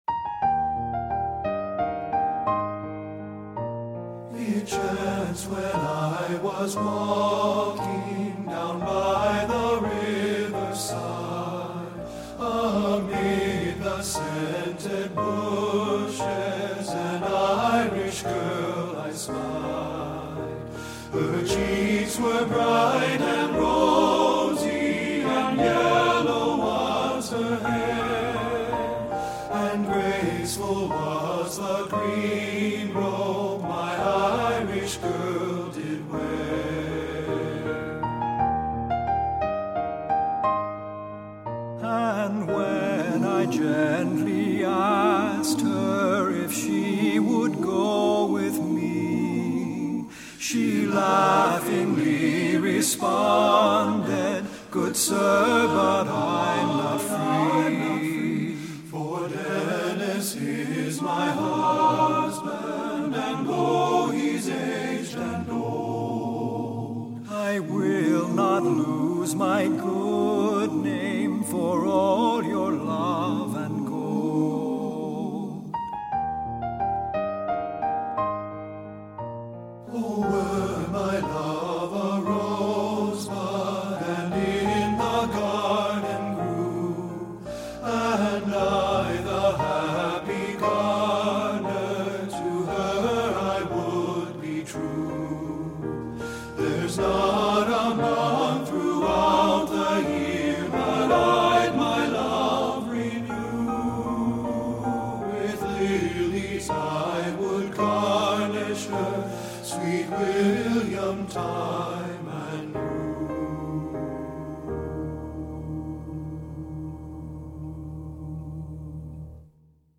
Irish Folk Song
• Solo Tenor
• Tenor 1
• Tenor 2
• Bass
• Keyboard
Studio Recording
There is also an opportunity for a featured soloist.
Ensemble: Tenor-Bass Chorus
Key: E major
Accompanied: Accompanied Chorus